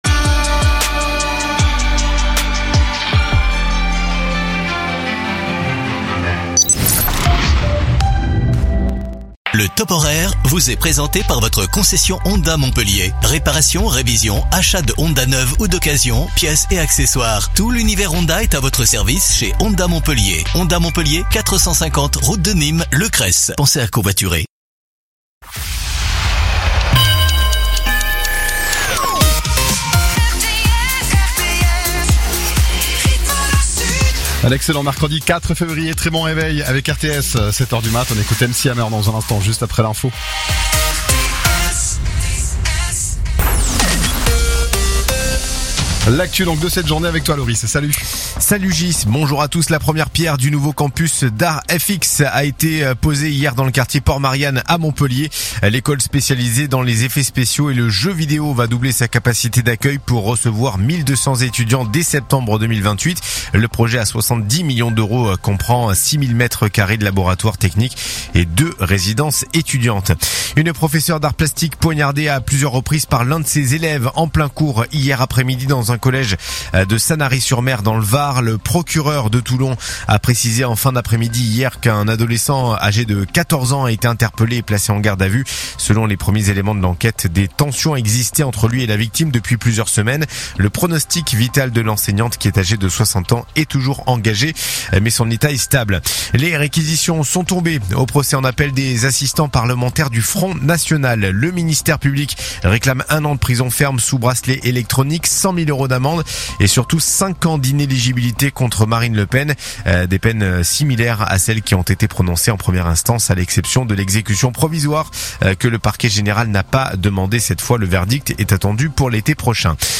RTS : Réécoutez les flash infos et les différentes chroniques de votre radio⬦
info_mtp_sete_beziers_649.mp3